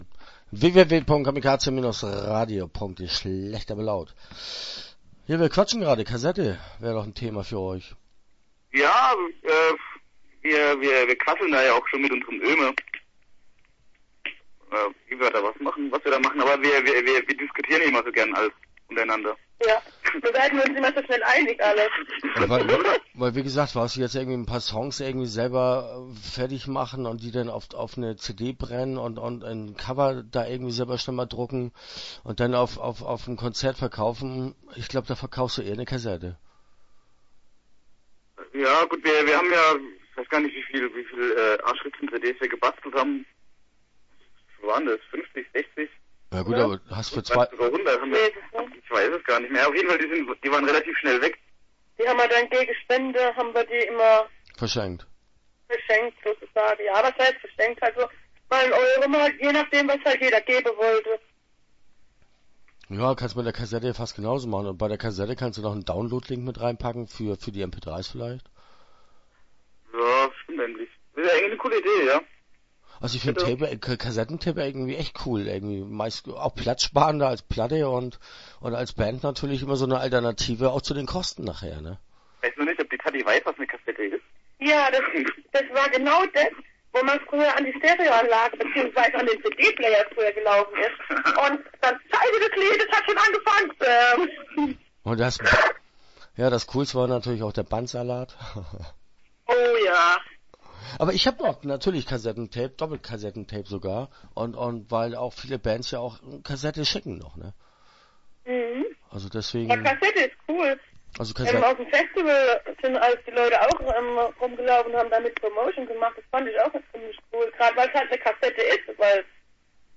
Schlecht aber Laut - Interview Teil 1 (6:30)